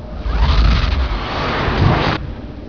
*Stargate being activated*